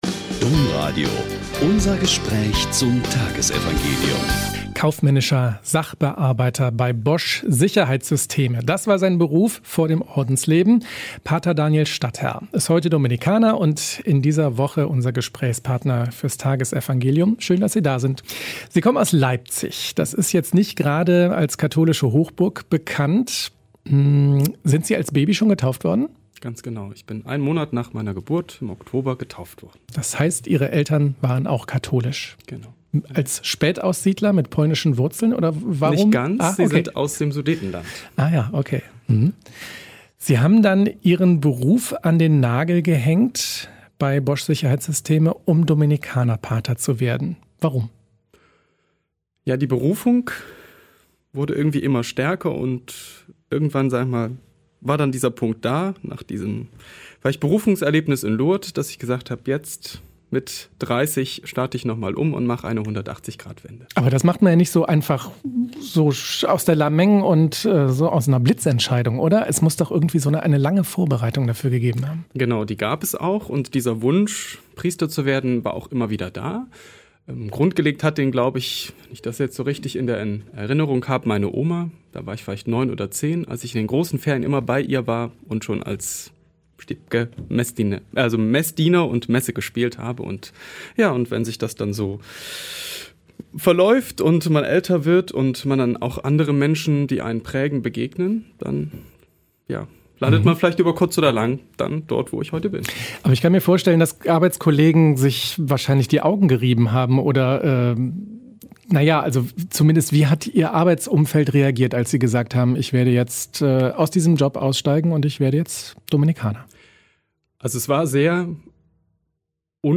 Mt 23,23-26 - Gespräch